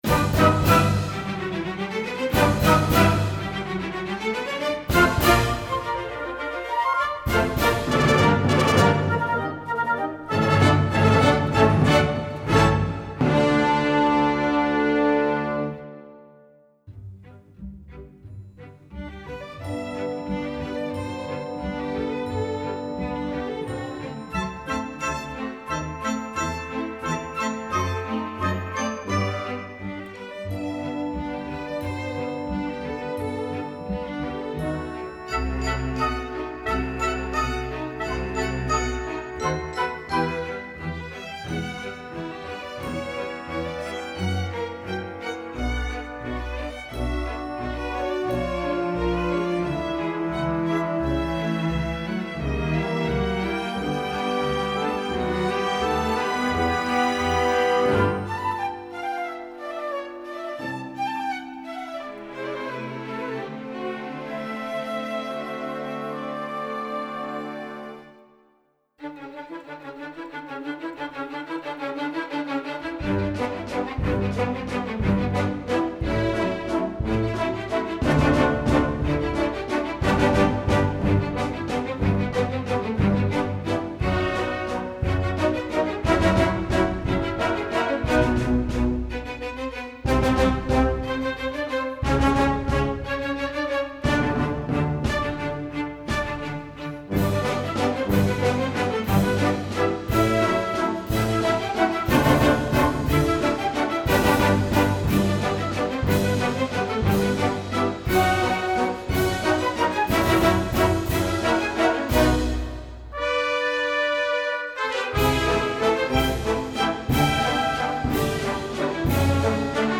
Instrumentation: full orchestra
classical, children